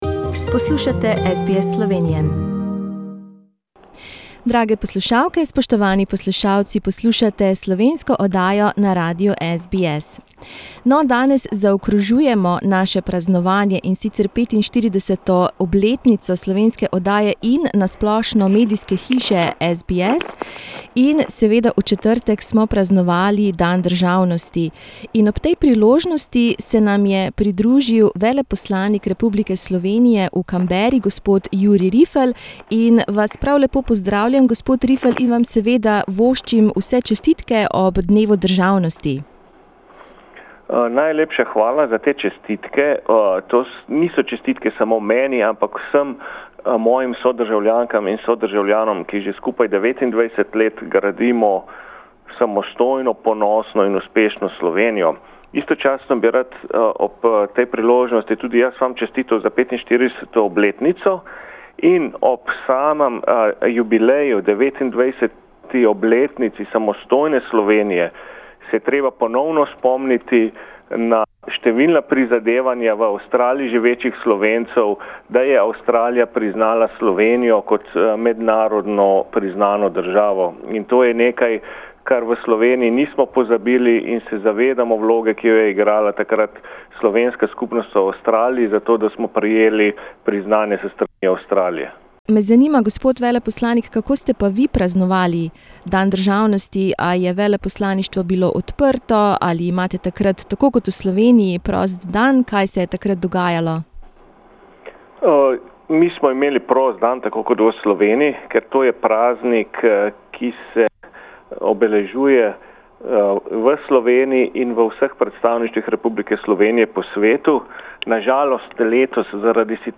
Njegova ekscelenca Jurij Rifelj, veleposlanik Republike Slovenije, je ob dnevu državnosti nagovoril poslušalce.